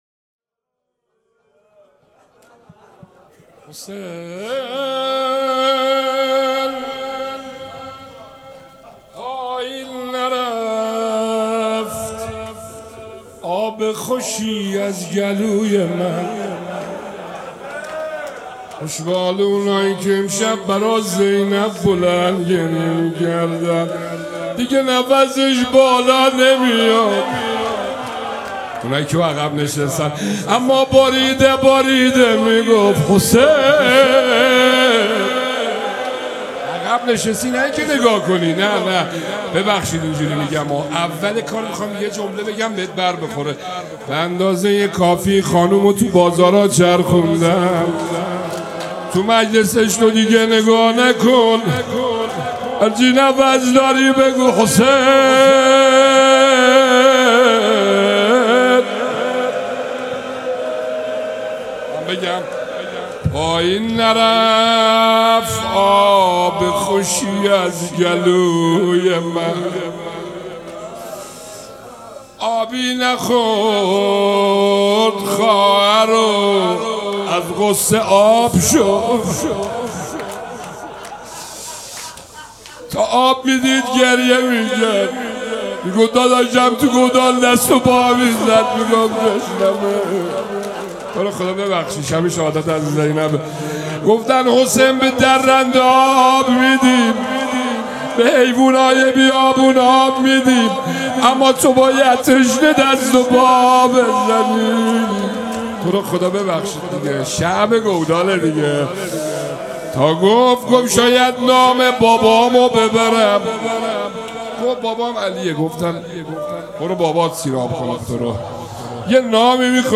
روضه حضرت زینب (س)
مراسم عزاداری شب شهادت حضرت زینب (سلام‌الله‌علیها)چهارشنبه ۲۷ و پنجشنبه ۲۸ بهمن ۱۴۰۰، از نماز مغرب و عشاء ‌‌‌حسینیه ریحانة‌الحسین (سلام‌الله‌علیها)
سبک اثــر روضه